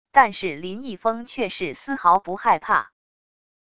但是林一峰却是丝毫不害怕wav音频生成系统WAV Audio Player